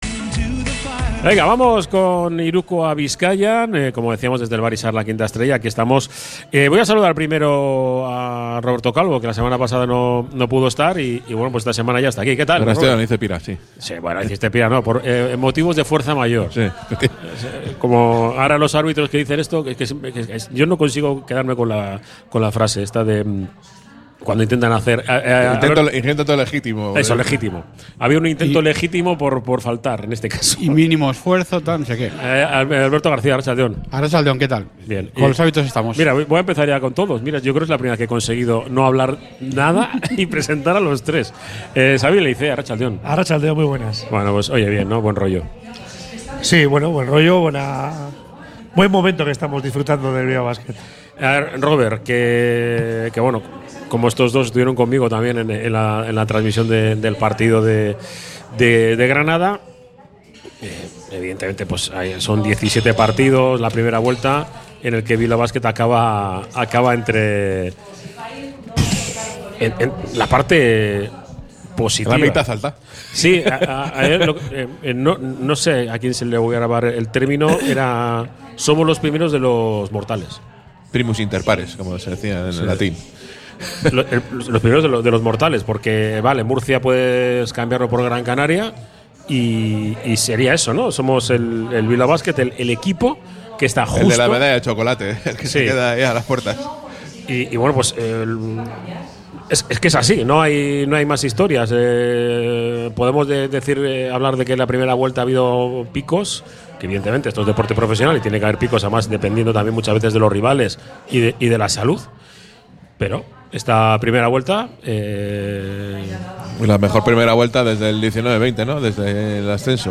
Desde el Bar Izar